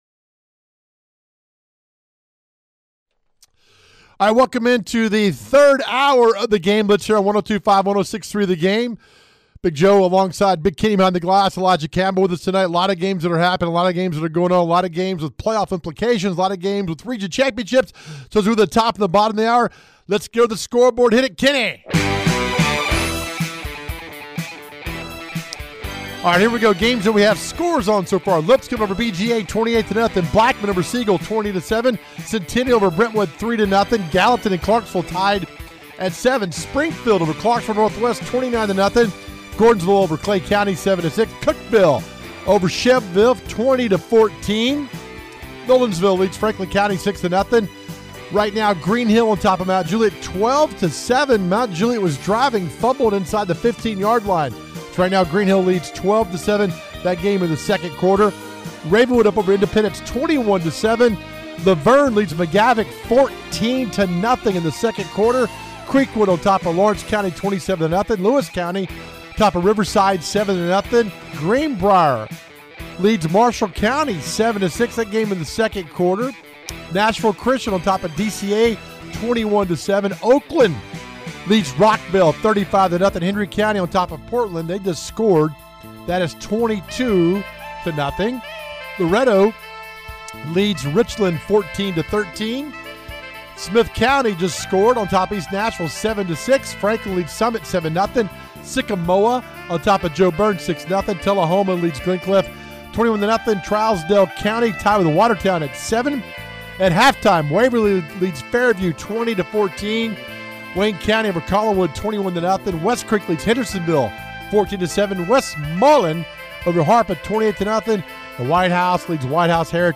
They will bring you everything in the world of Middle TN High School Football. We have reporters on location as well as coaches interviews and scoreboard updates.